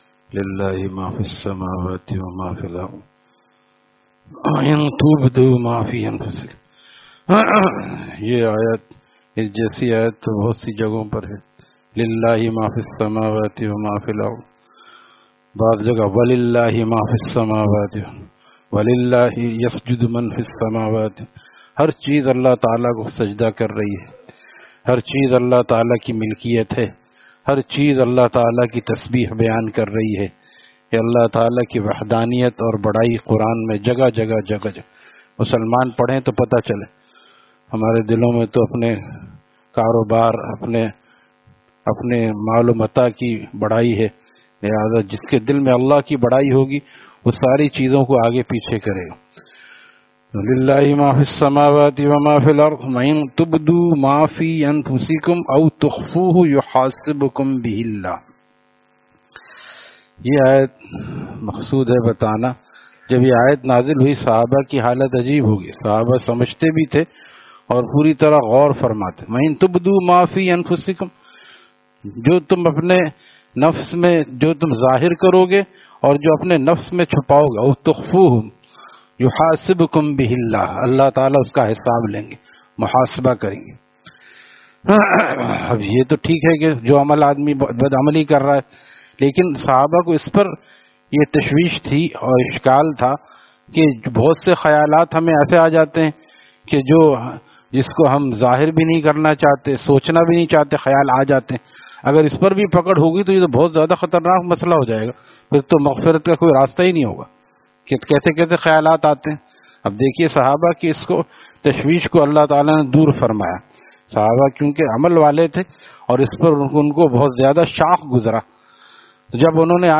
Taleem After Fajor at Jamia Masjid Gulzar e Muhammadi, Khanqah Gulzar e Akhter, Sec 4D, Surjani Town